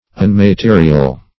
Unmaterial \Un`ma*te"ri*al\, a. Not material; immaterial.